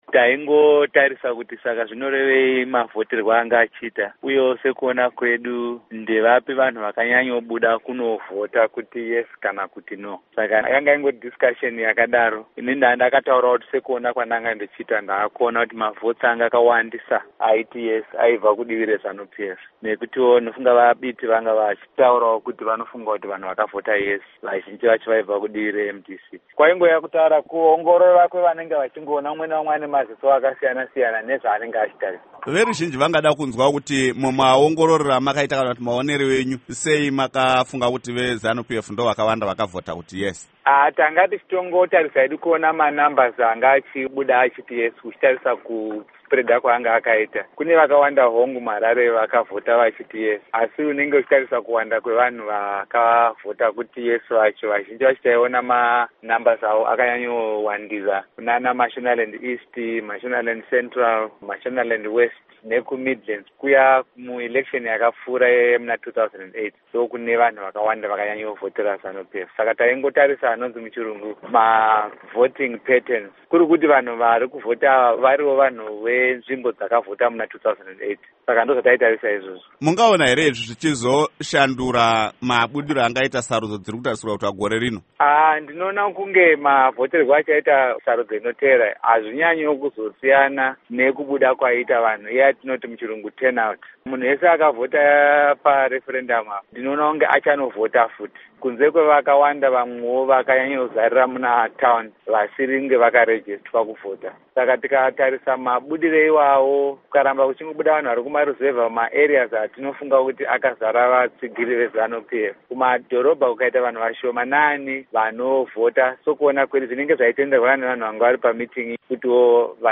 Hurukuro naProfessor Lovemore Madhuku